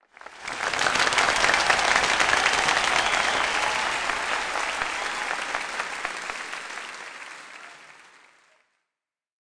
Applause Sound Effect
Download a high-quality applause sound effect.
applause-11.mp3